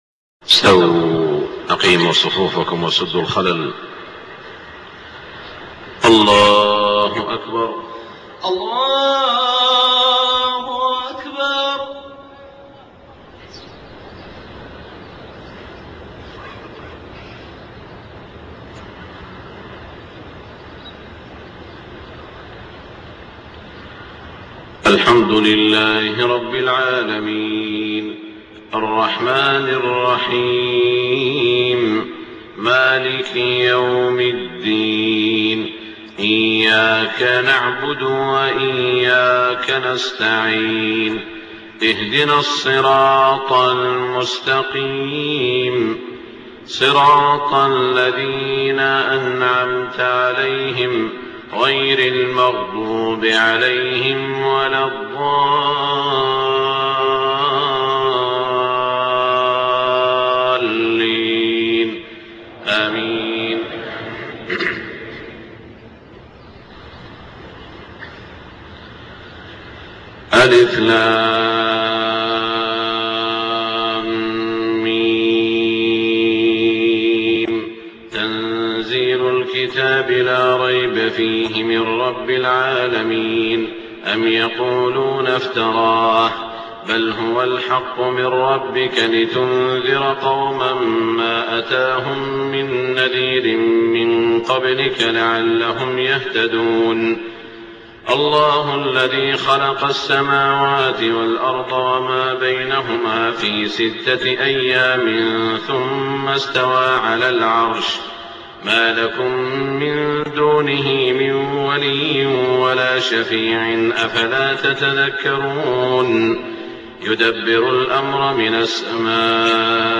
صلاة الفجر 17 رجب 1427هـ سورتي السجدة و الإنسان > 1427 🕋 > الفروض - تلاوات الحرمين